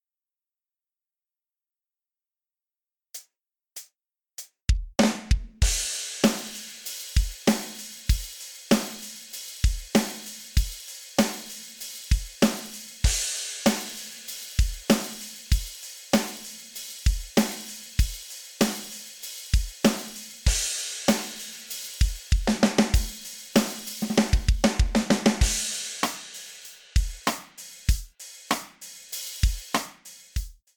There are lots of patterns included so you can easily build a track that doesn't have that fake synth drum feel. I threw together a quick drum track for a song the other night in about 30 minutes. This should give you and idea of what it sounds like.